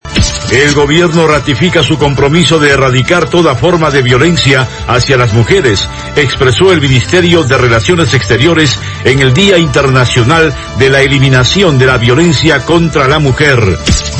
Titulares